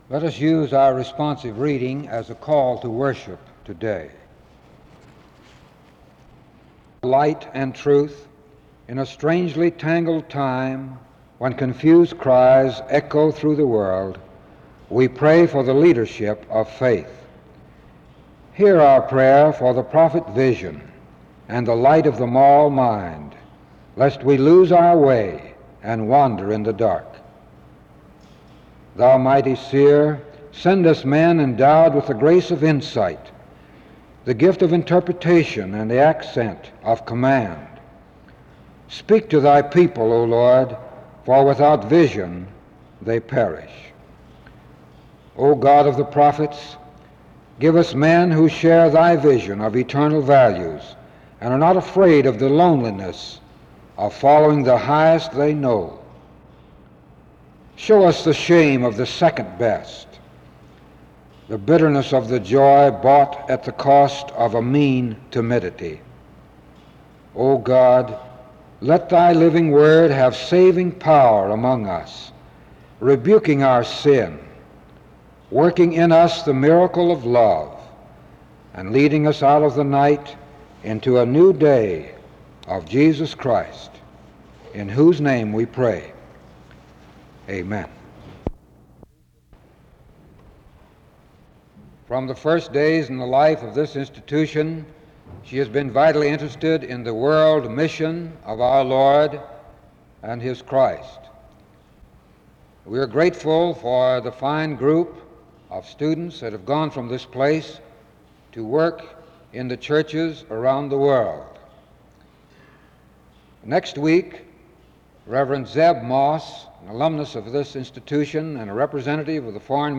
The service begins with a word of prayer from 0:00-1:30.